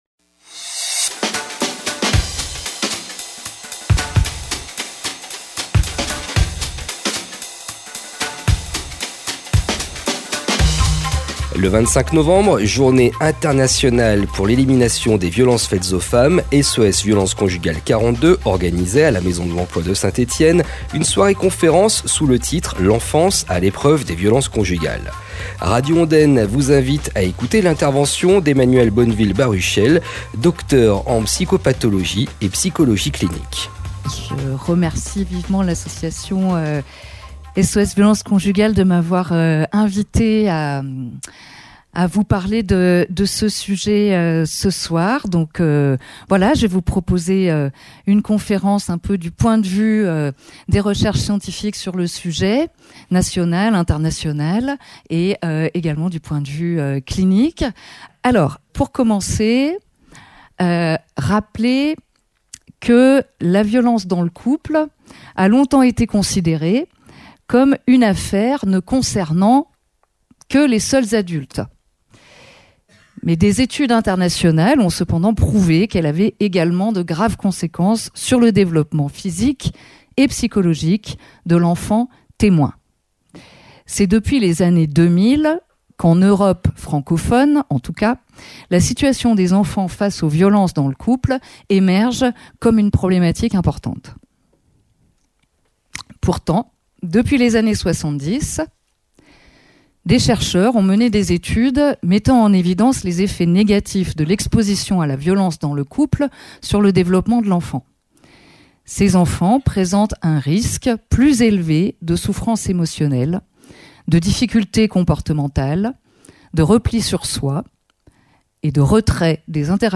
Le 25 Novembre, à l’occasion de la Jounrée Internationale pour l’Elimination des Violences Faites aux Femmes, SOS Violences Conjugales 42 organisait une soirée conférence débat, sous le titre L’ENFANCE A L’EPREUVE DES VIOLENCES CONJUGALES.